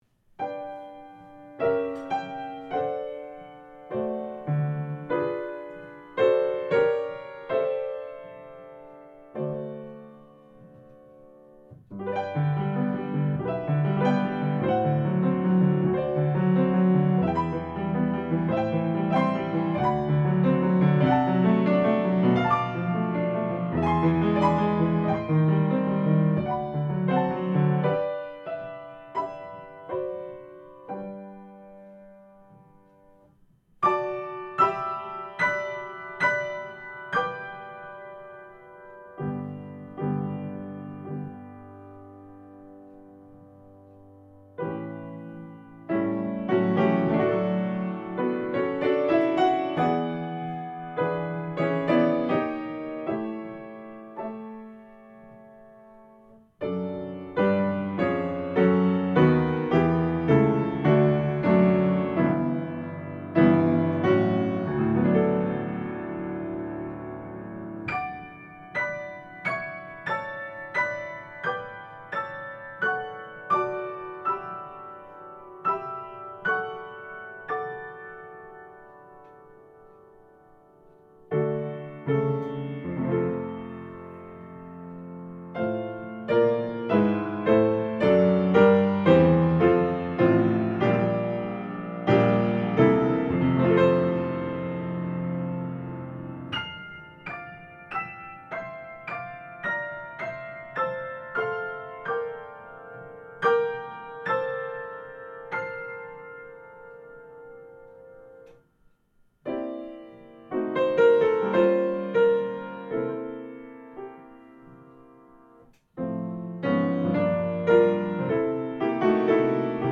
The Original Piano Bell and Chime Novelty